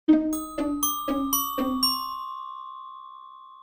game_over_sfx.mp3